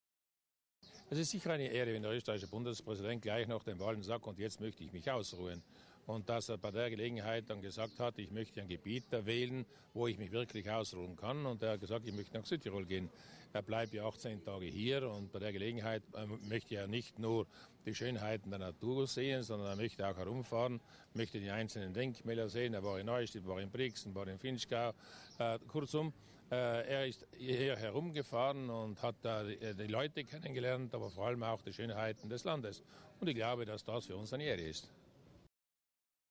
Bundespräsident Fischer über die Schönheiten der Gärten von Schloss Trauttmansdorff